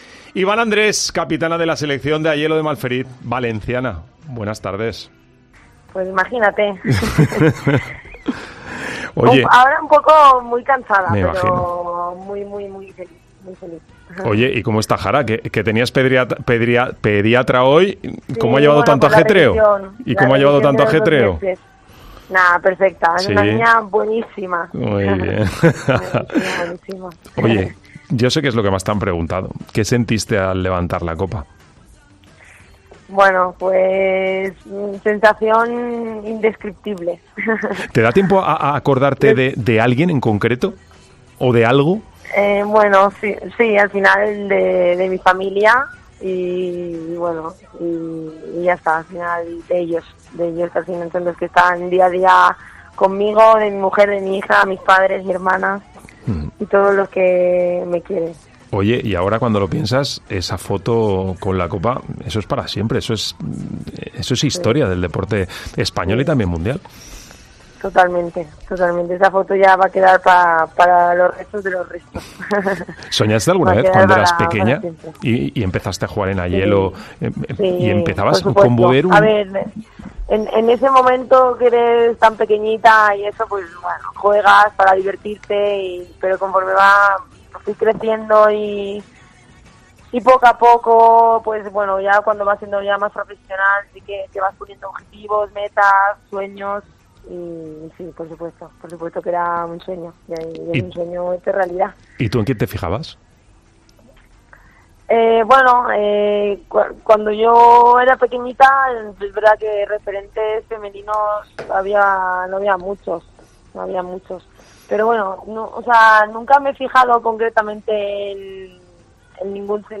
ENTREVISTA COPE
Las valencianas Ivana Andrés y Enith Salón y la albaceteña Alba Redondo, jugadora del Levante UD, atienden a COPE tras llegar a España con la estrella en el pecho